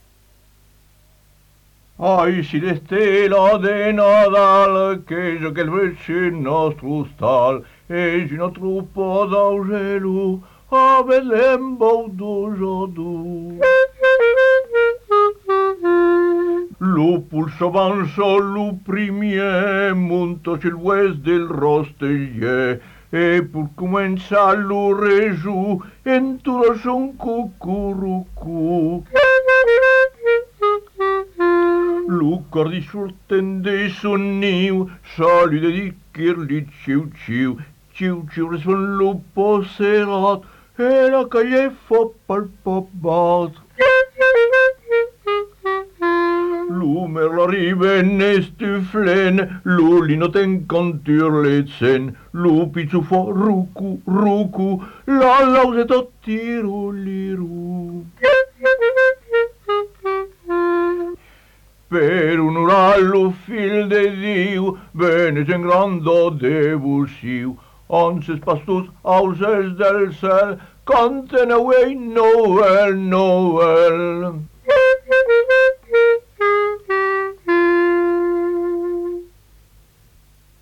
43 Vieux chants du Quercy en Acapella